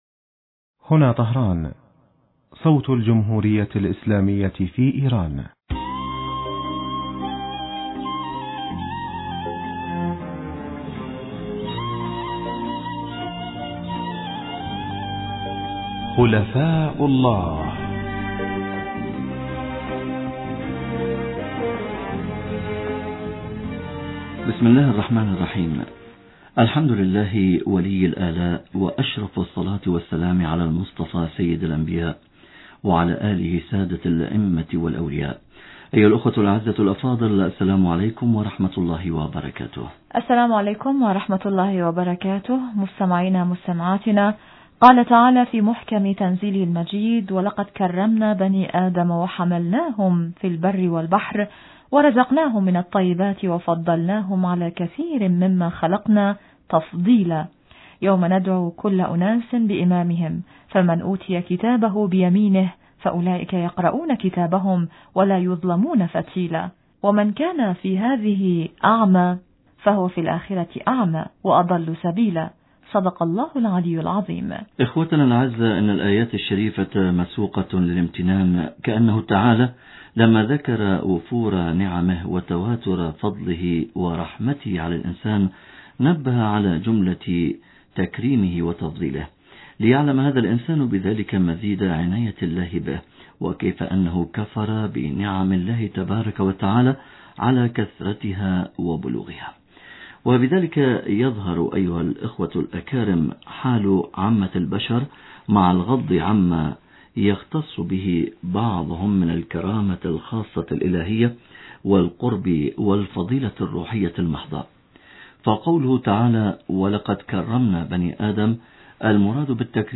إضافة الي النص المتقدم توجد الكثير من الاحاديث الشريفة تصف ائمة العترة المحمدية عليهم السلام بأنهم امناء الله في ارضه فما معنى هذا الوصف؟ نتابع الحلقة بأجابة الى السؤال المتقدم من ضيف البرنامج في هذا اللقاء